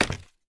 sounds / step / scaffold1.ogg
scaffold1.ogg